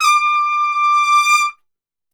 D 4 TRPSWL.wav